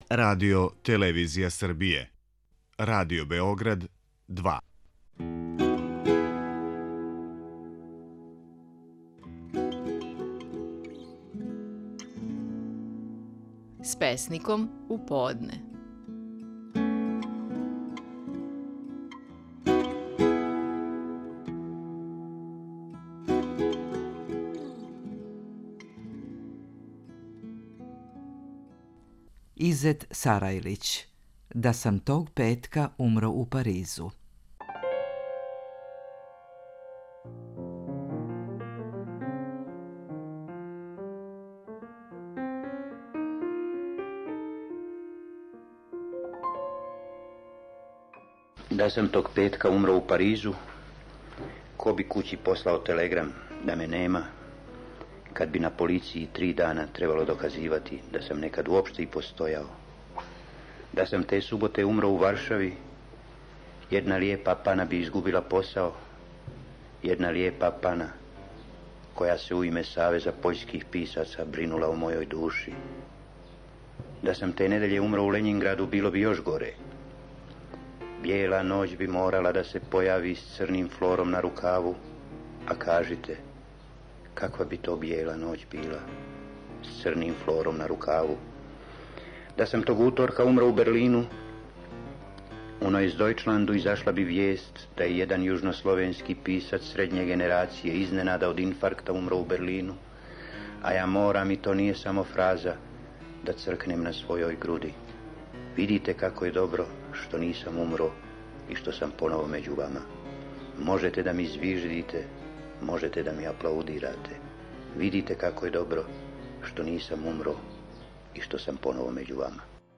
Стихови наших најпознатијих песника, у интерпретацији аутора.
Изет Сарајлић говори своју песму „Да сам тог петка умро у Паризу".